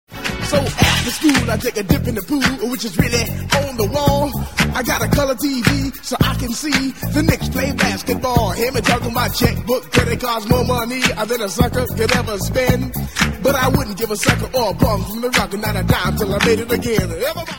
Considered to be the first commercially released rap song